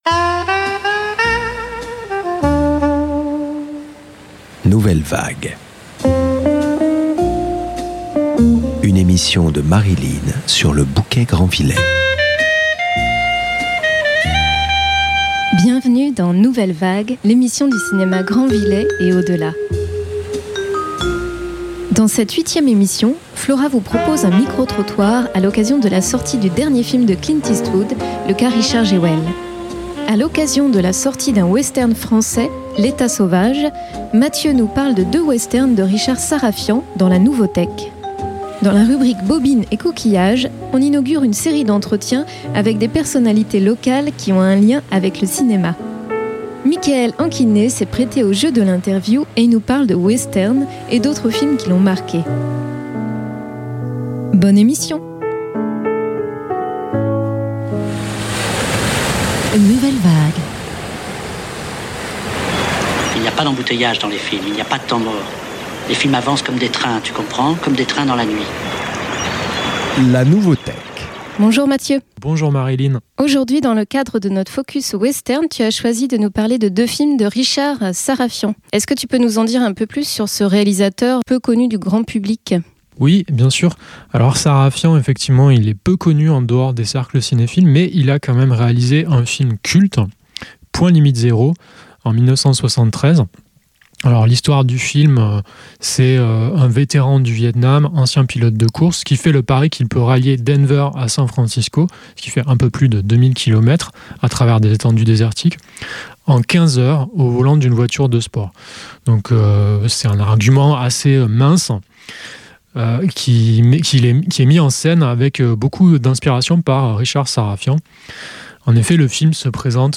Et, dans la rubrique Bobines et coquillages, on inaugure une série d’entretiens avec des personnalités locales qui ont un lien avec le cinéma.
Les extraits de musiques de films qui ponctuent l’émission